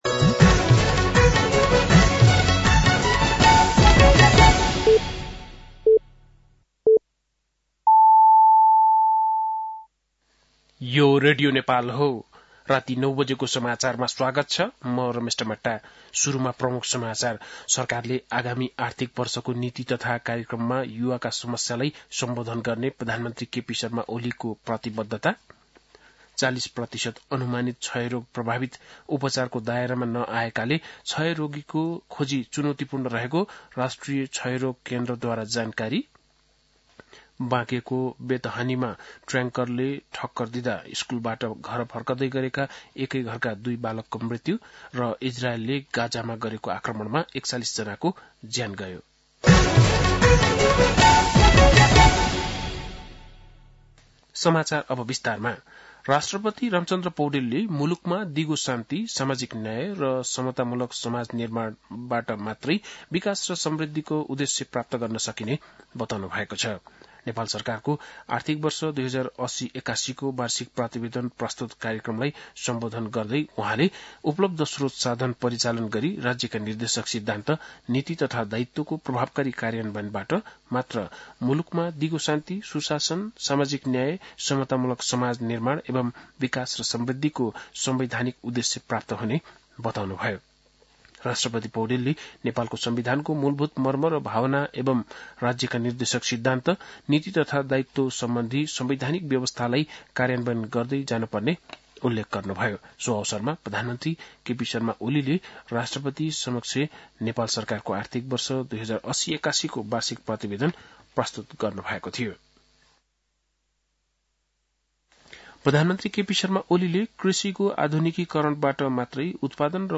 बेलुकी ९ बजेको नेपाली समाचार : १० चैत , २०८१
9-pm-nepali-news-12-10.mp3